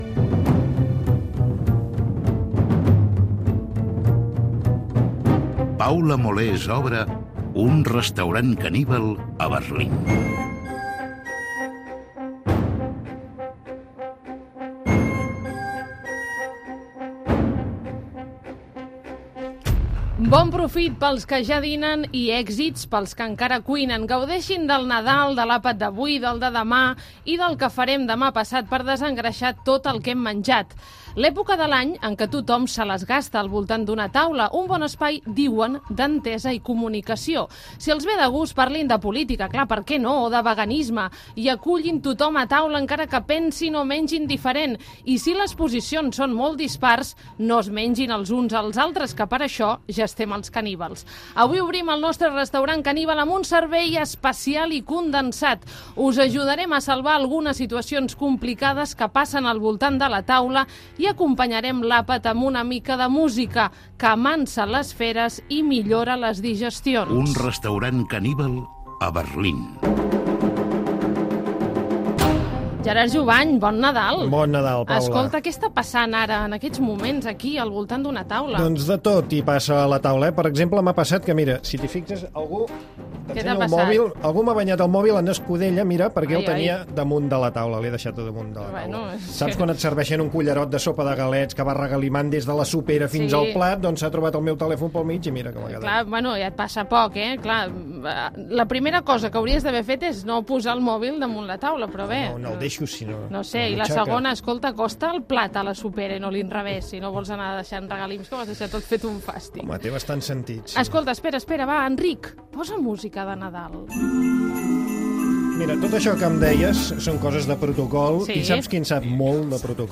Careta, presentació, indicatiu, els àpats de Nadal, el protocol i el comportament
Gènere radiofònic Entreteniment